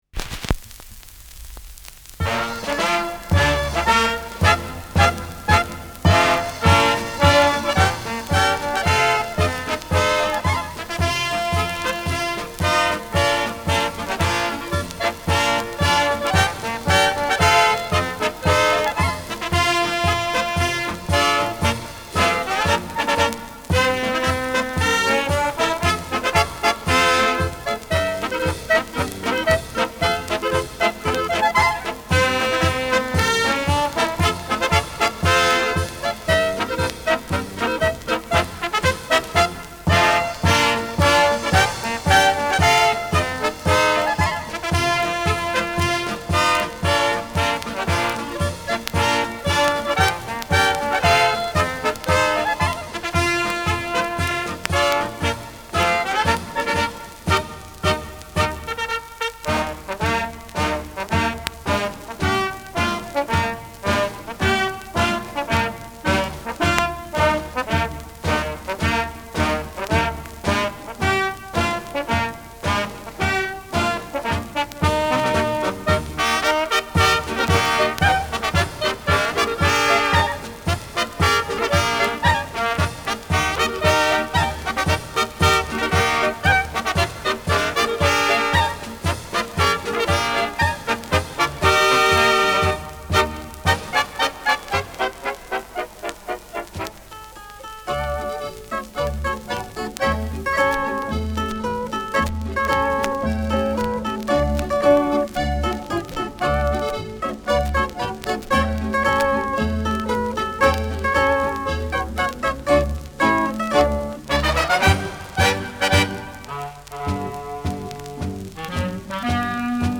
Schellackplatte
Tonrille: Kratzer Durchgehend Leicht : Kratzer 1 Uhr Stärker
Abgespielt : Gelegentlich stärkeres Knacken
[München] (Aufnahmeort)